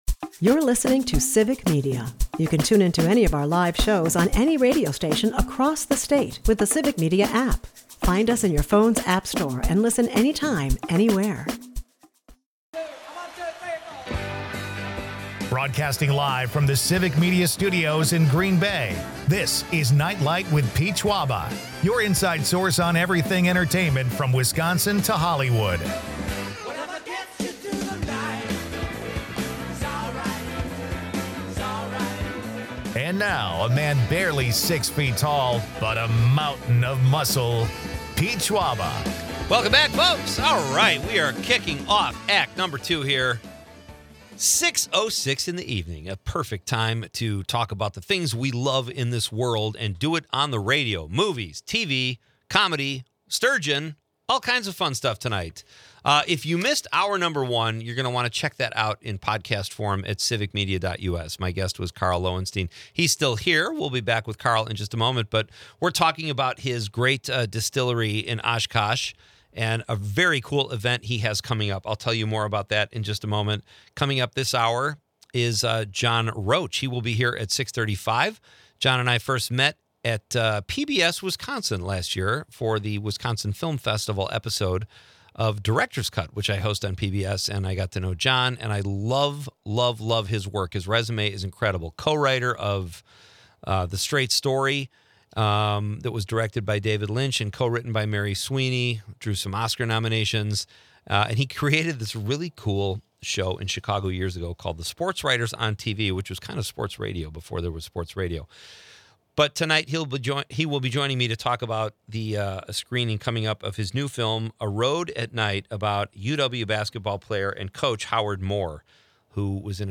Amidst these lively discussions, listeners weigh in with their favorite slow dance songs, sparking a nostalgic debate.